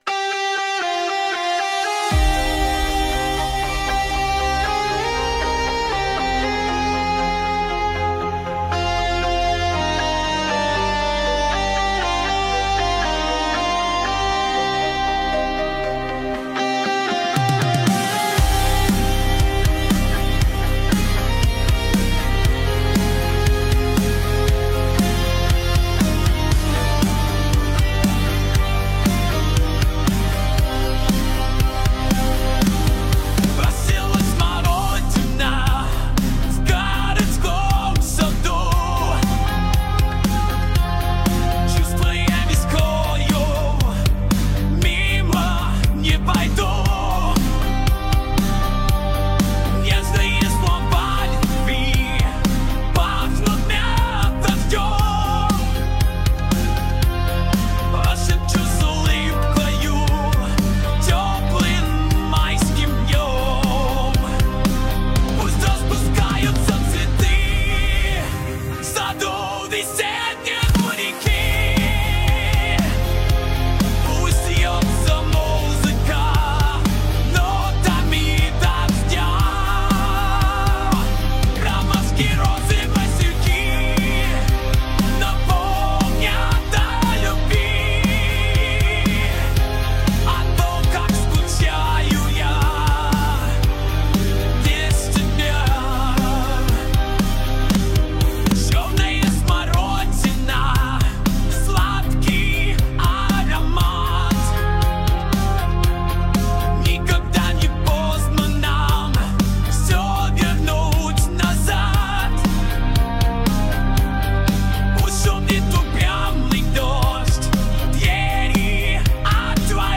(Рок версия)